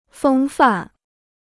风范 (fēng fàn): air; manner.